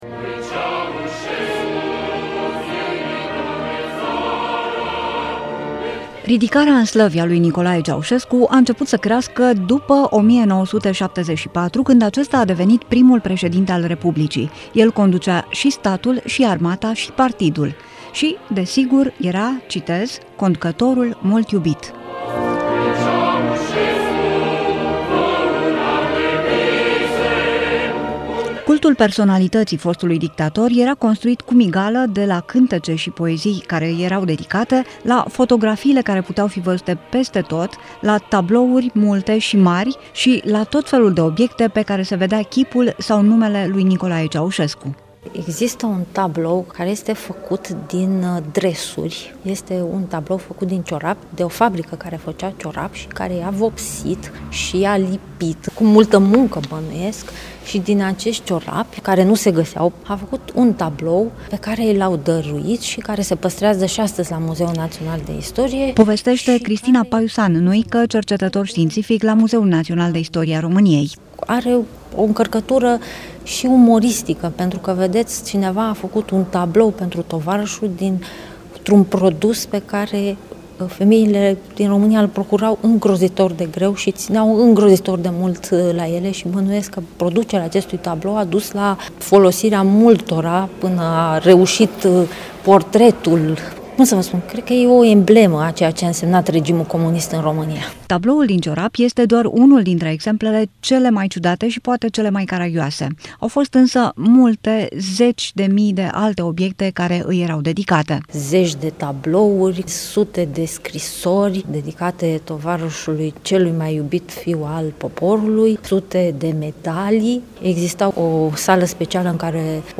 REPORTAJ-cultul-personalitatii-CEAUSESCU-.mp3